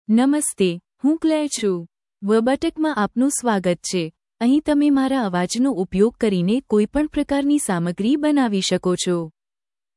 Claire — Female Gujarati (India) AI Voice | TTS, Voice Cloning & Video | Verbatik AI
Claire is a female AI voice for Gujarati (India).
Voice sample
Listen to Claire's female Gujarati voice.
Claire delivers clear pronunciation with authentic India Gujarati intonation, making your content sound professionally produced.